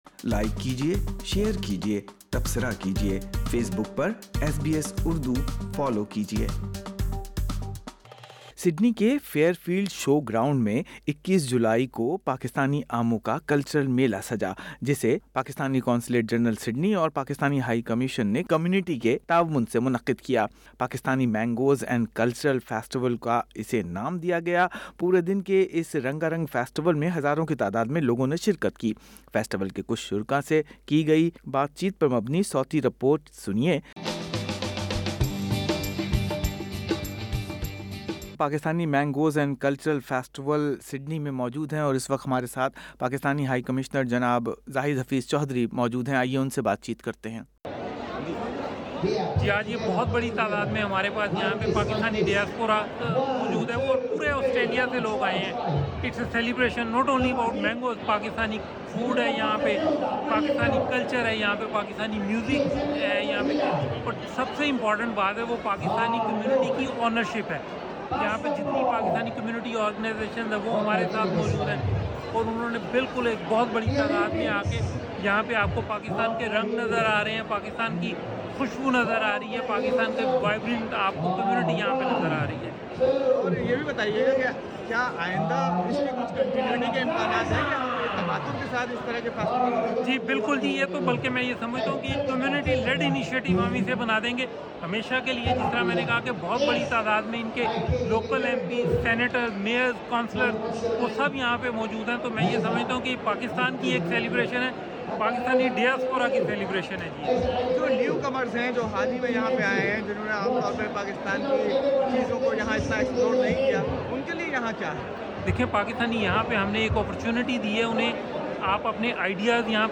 ایس بی ایس اردو سے بات کرتے ہوئے پاکساتنی سفیر جناب ذاہد حفیظ چوہدری نے اس میلے کی کامیابی کو کمیونٹی کی کوششوں سے منسوب کیا جبکہ نئے آنے والےقونسلر جنرل سڈنی جناب قمر الزماں کا کہنا تھا کہ آئیندہ بھی اس طرح کے میلے منعقد کئے جاتے رہیں گے۔